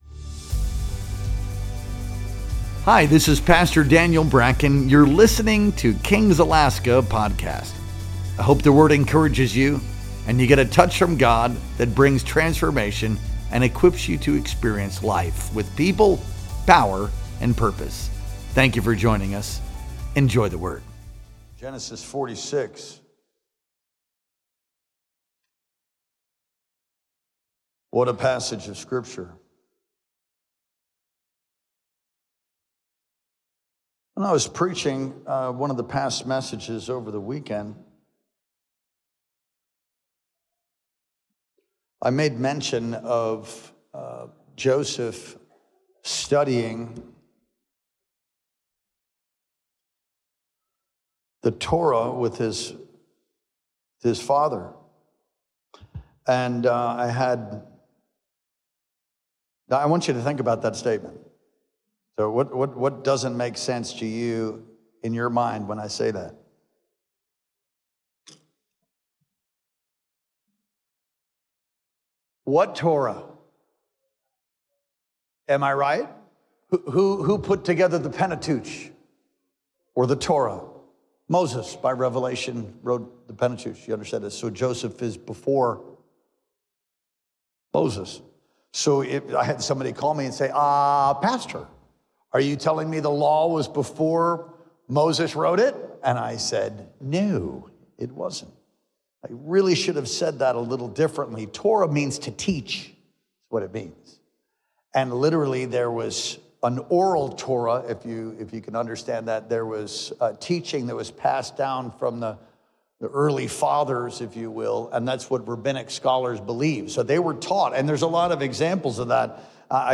Our Wednesday Night Worship Experience streamed live on June 18th, 2025.